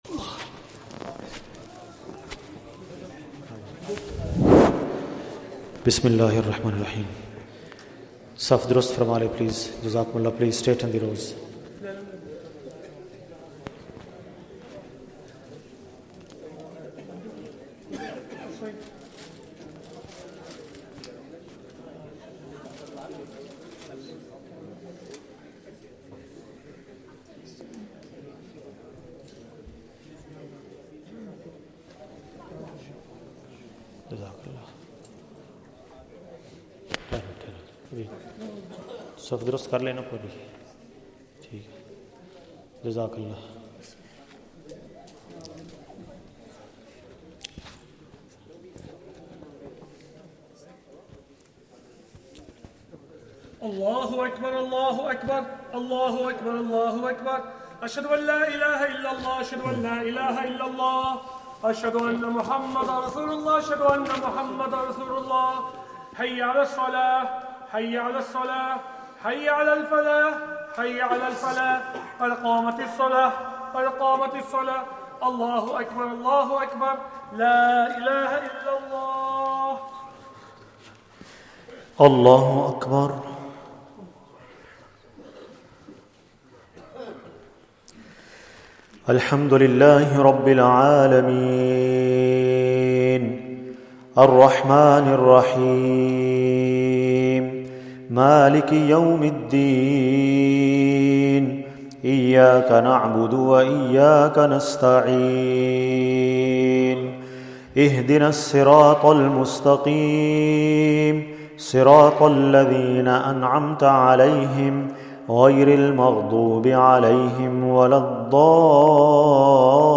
Taraweeh 29th night, khatam al Quran and Duaa